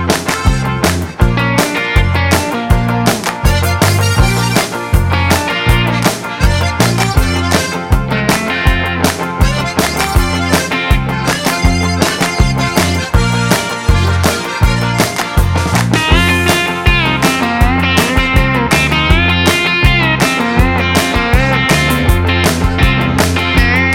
No Lead Guitar Pop (1980s) 2:31 Buy £1.50